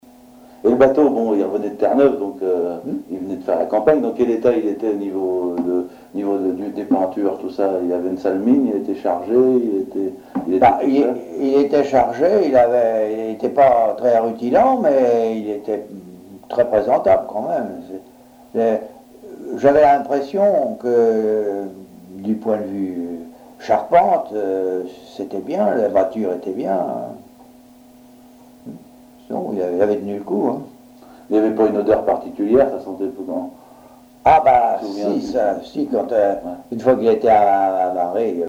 Témoignages sur la construction navale à Fécamp
Catégorie Témoignage